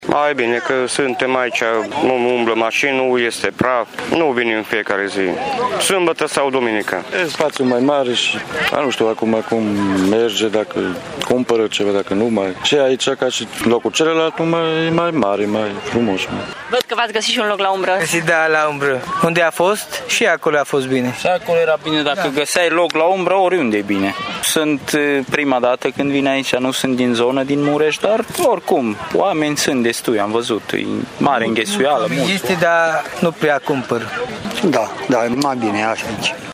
Comerțul ambulant specific se desfășoară într-un spațiu limitat dar suficient, spun comercianții: